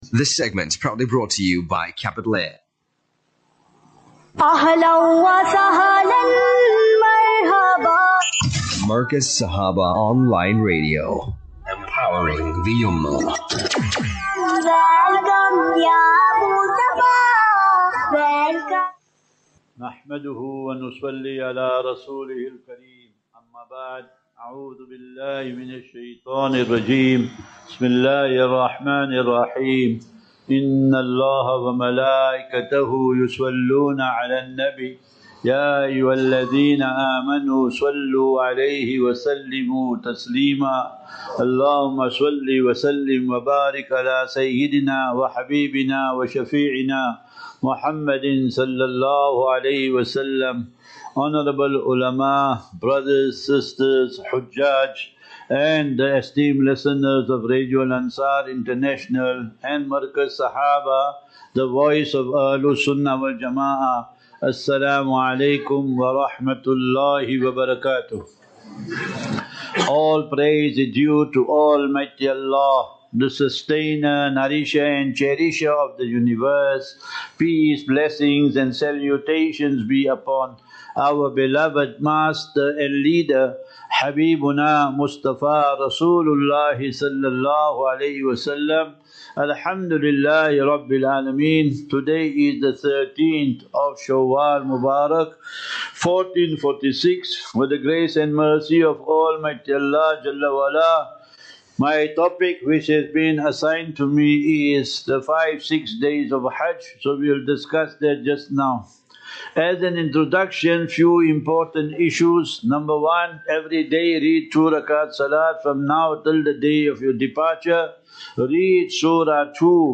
12 Apr Hajj Seminar 1446/2025
Lectures